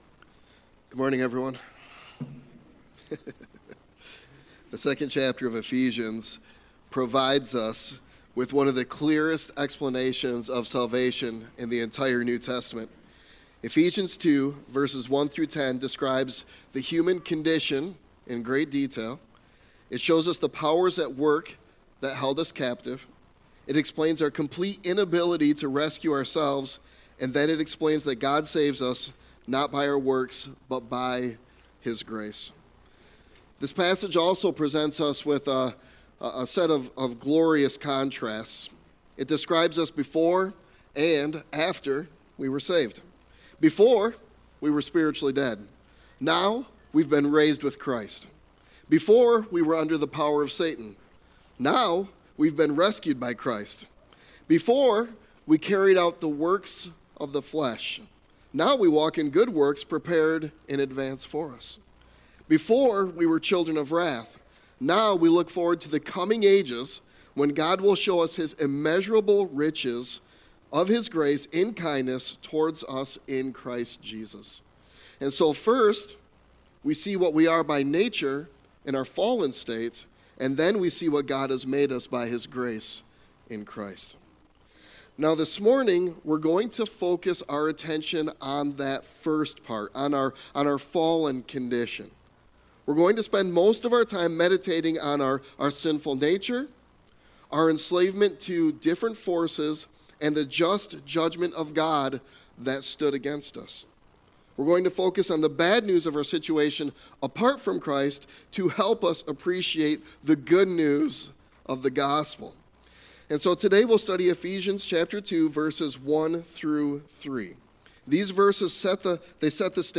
Sermon Text: Ephesians 2:1-3